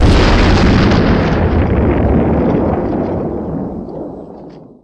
Blast10.wav